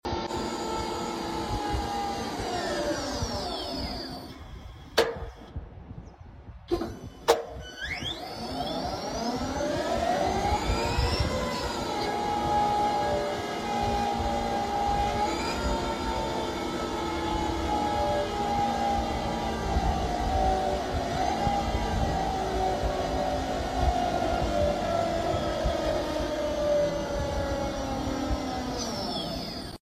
Liebherr EC B Hoist winch, Just sound effects free download